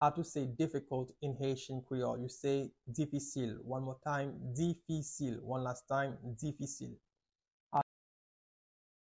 Listen to and watch “Difisil” audio pronunciation in Haitian Creole by a native Haitian  in the video below:
5.How-to-say-Difficult-in-Haitian-Creole-Difisil-pronunciation.mp3